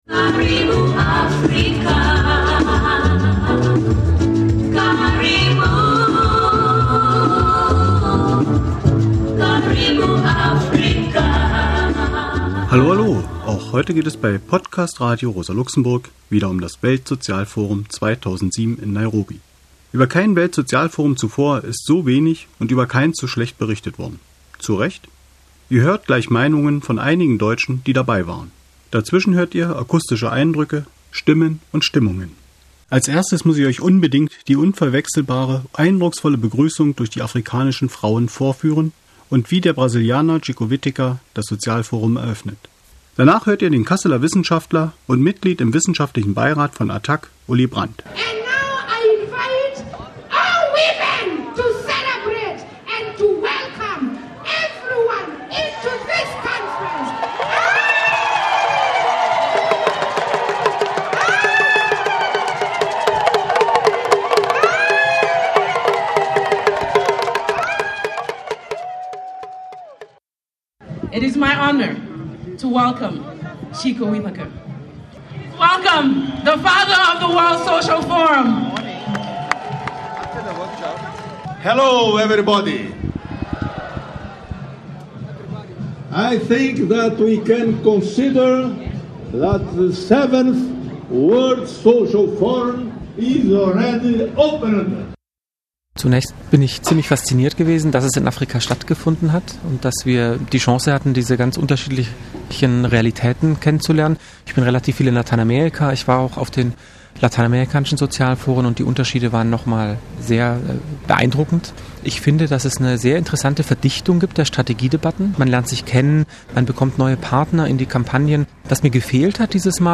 Stimmen und Stimmungen Kurze akustische Eindrücke und die Meinungen einiger der deutschen TeilnehmerInnen